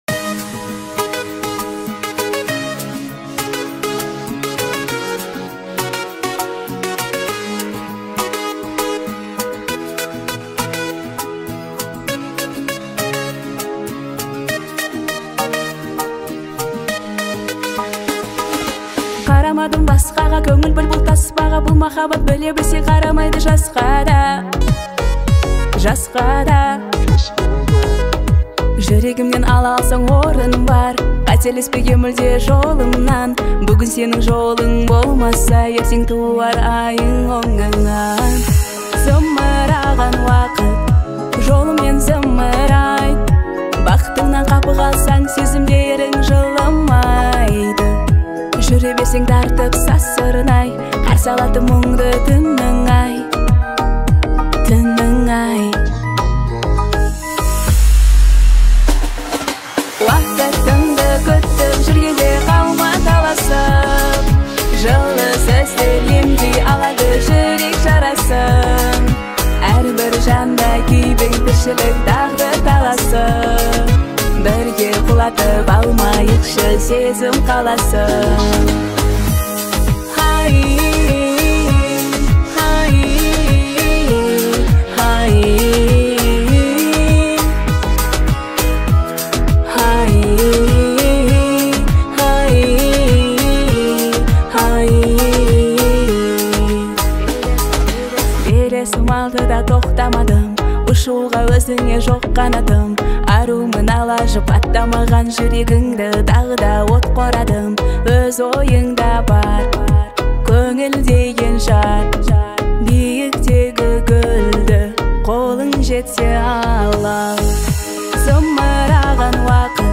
• Узбекские песни